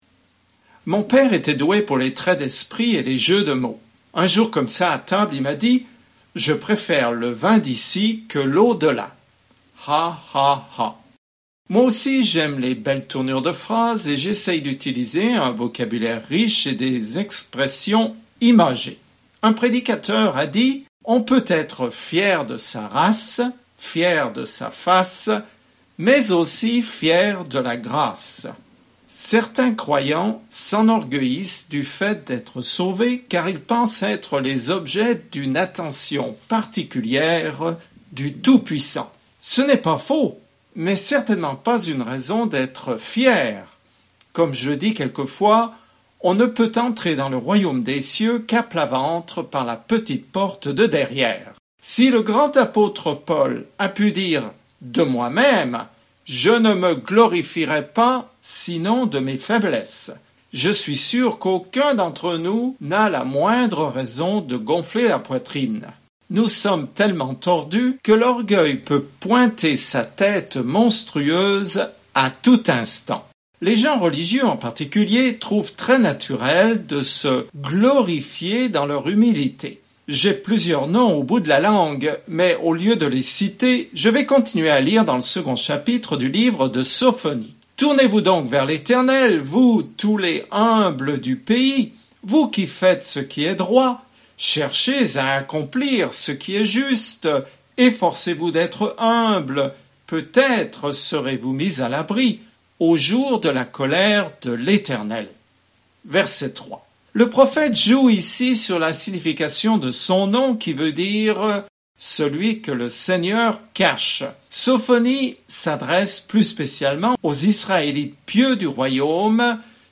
Écritures Sophonie 2:3-10 Jour 4 Commencer ce plan Jour 6 À propos de ce plan Sophonie prévient Israël que Dieu va les juger, mais leur dit aussi combien il les aime et comment un jour il se réjouira d'eux en chantant. Parcourez quotidiennement Sophonie en écoutant l’étude audio et en lisant des versets sélectionnés de la parole de Dieu.